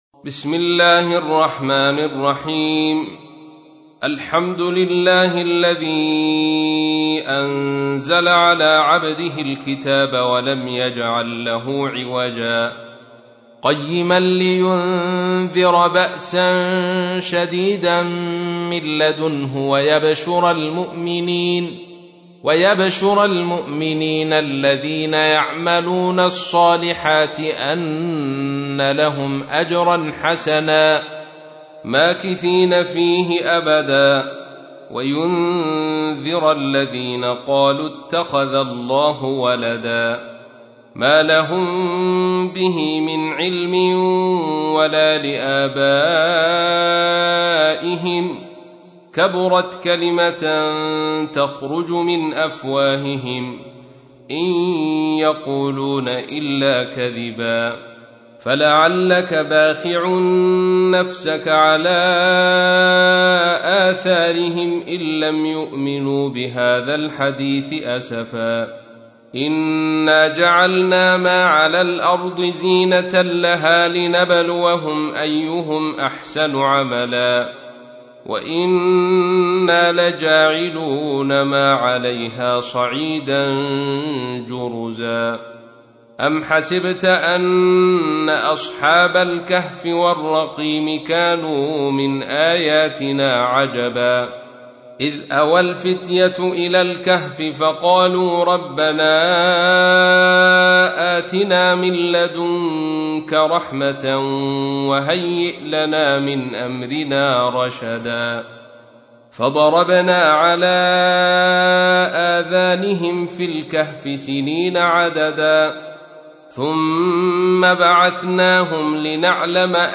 تحميل : 18. سورة الكهف / القارئ عبد الرشيد صوفي / القرآن الكريم / موقع يا حسين